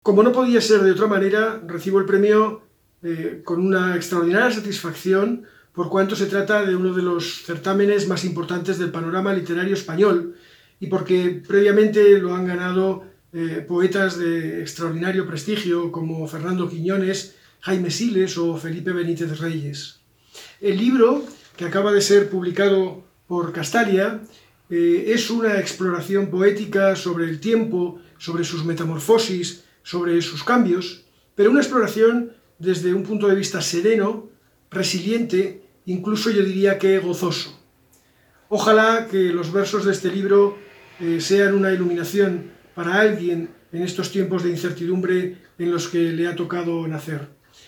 Todos los premiados  agradecen el galardón en una gala difundida en Youtube con intervención de todos los jurados y responsables institucionales